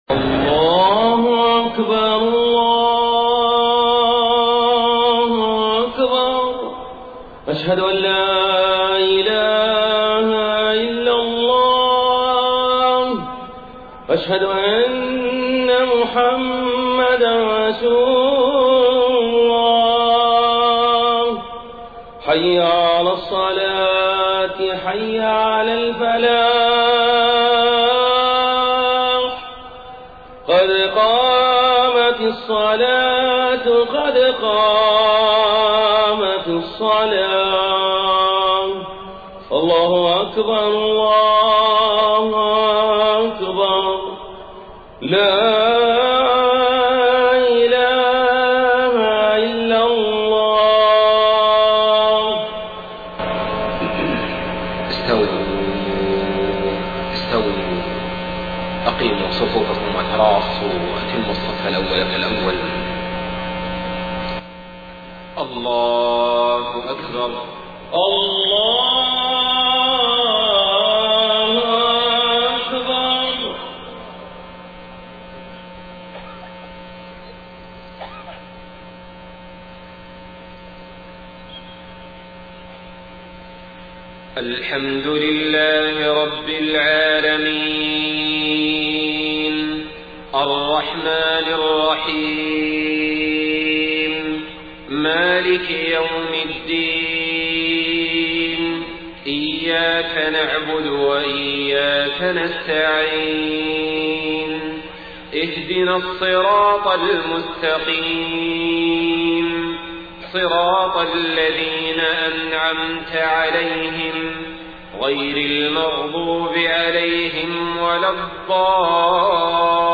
صلاة المغرب 15 ربيع الأول 1431هـ سورتي الفيل و قريش > 1431 🕋 > الفروض - تلاوات الحرمين